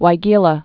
(wī-gēlə, -jē-, wījə-)